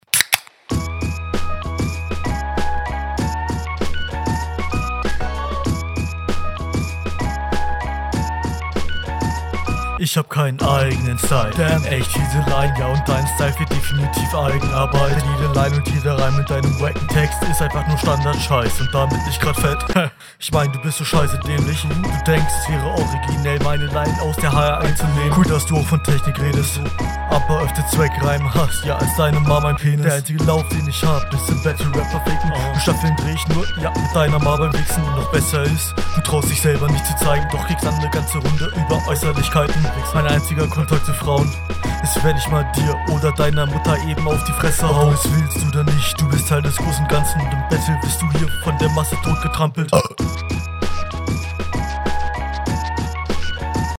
Flow: Der Flow ist solider und delivery ist auch nice Text: Die zweckreim line ist …
Soundqualität: das klackern am anfang.